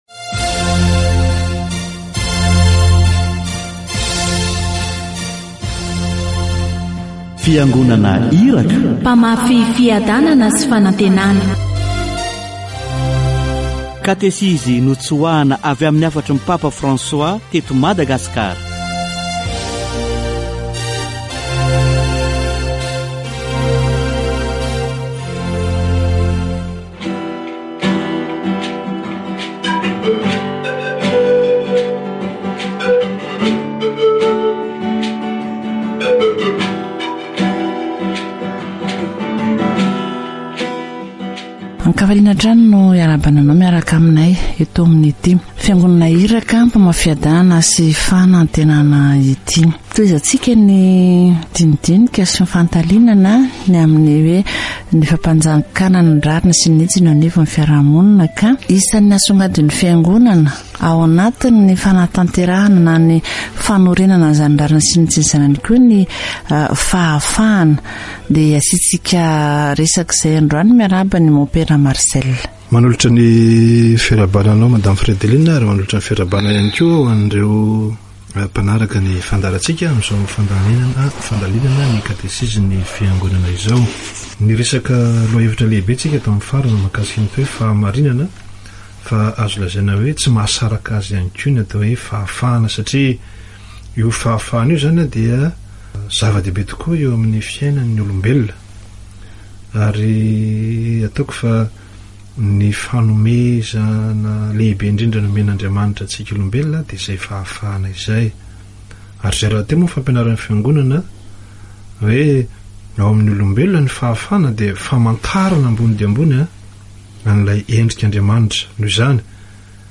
Catéchèse sur la justice et l'équité